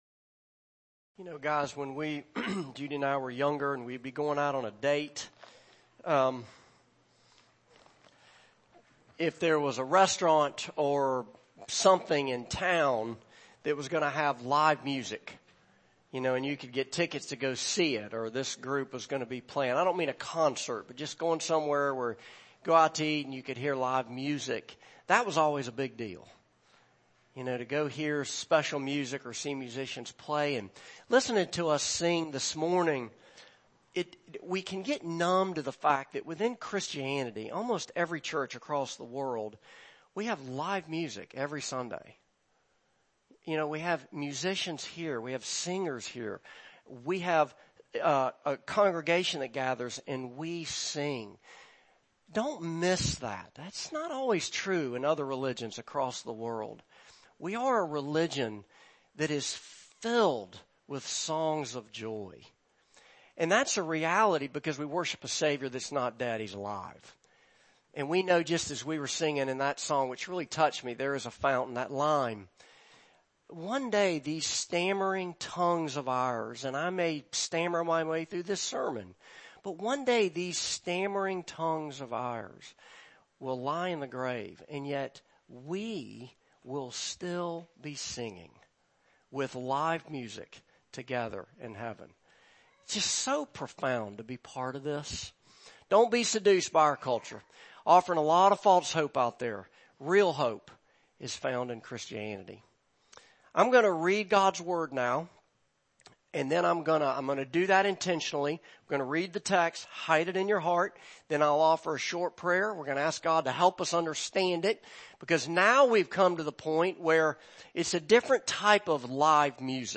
Passage: Hebrews 10:10,14,19-31 Service Type: Morning Service